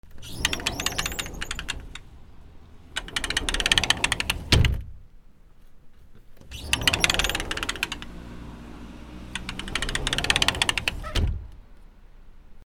サッシ
/ K｜フォーリー(開閉) / K05 ｜ドア(扉)